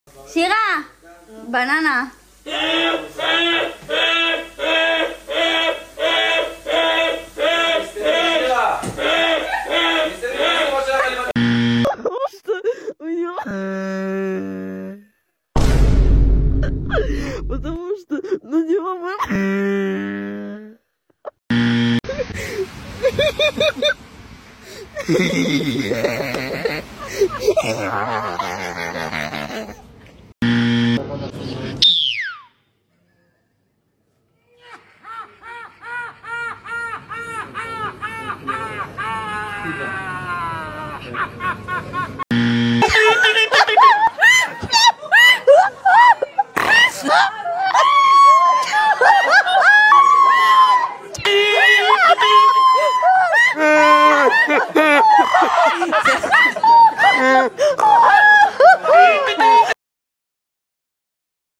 Ranking craziest unexpected laughs 🤣 sound effects free download